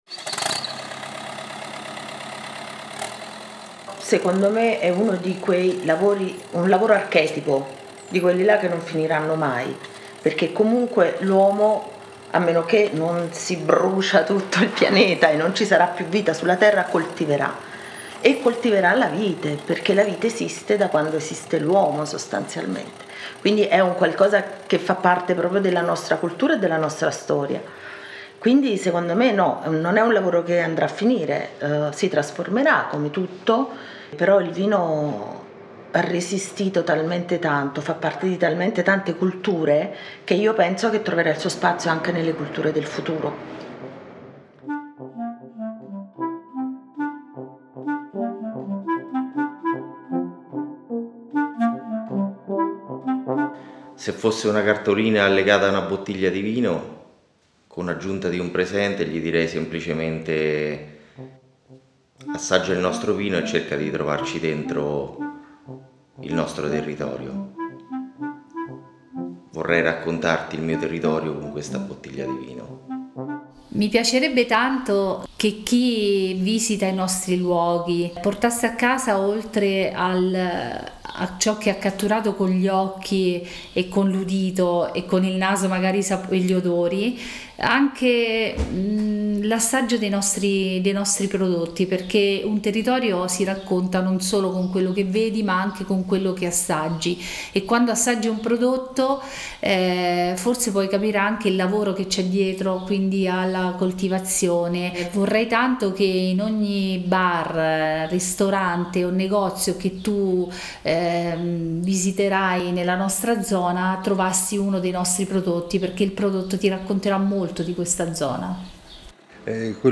sound design e montaggio
voci narranti